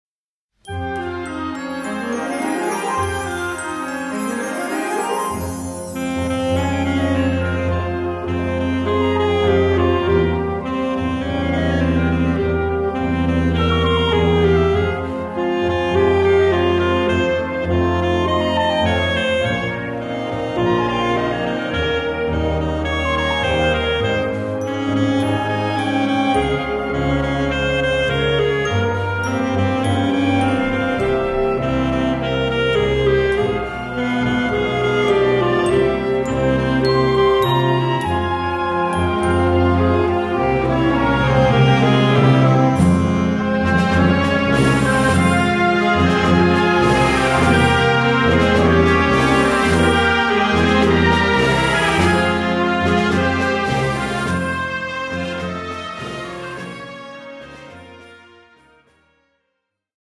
Gattung: Konzertante Blasmusik
A4 Besetzung: Blasorchester Zu hören auf